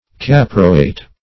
caproate - definition of caproate - synonyms, pronunciation, spelling from Free Dictionary Search Result for " caproate" : The Collaborative International Dictionary of English v.0.48: Caproate \Cap"ro*ate\, n. (Chem.) A salt of caproic acid.